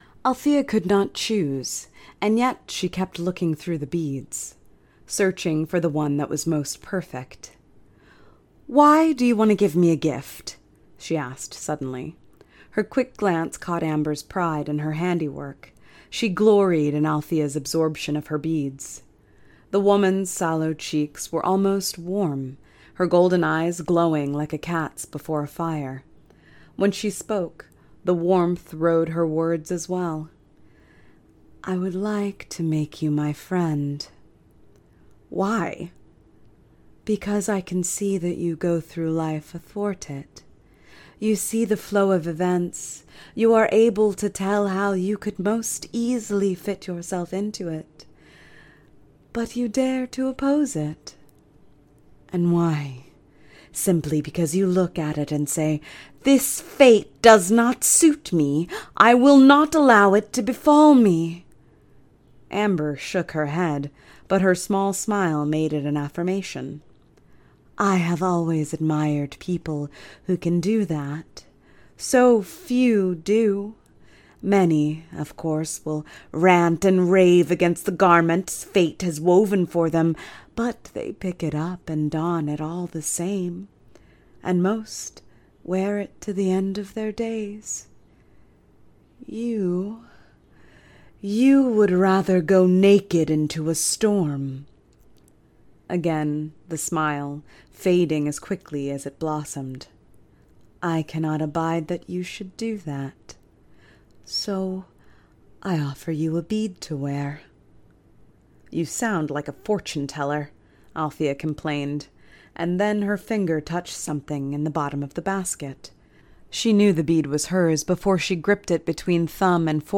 Audiobook Reel